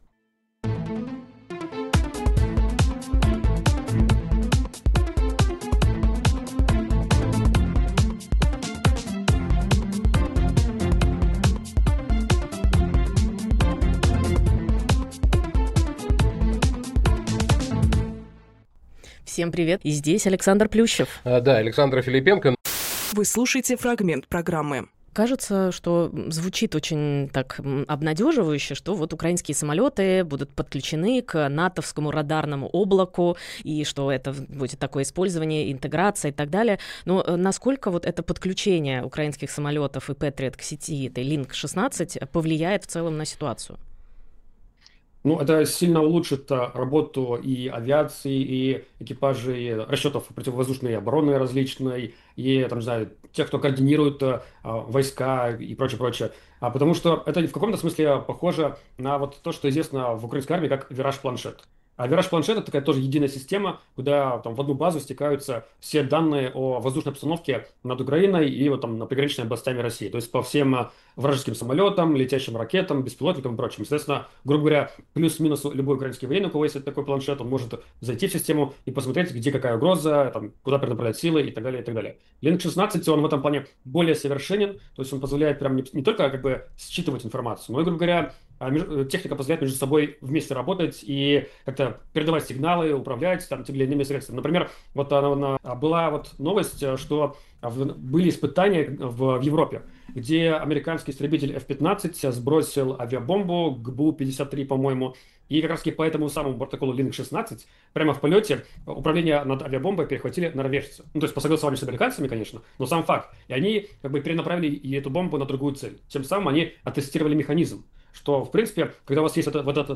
Фрагмент эфира от 01.06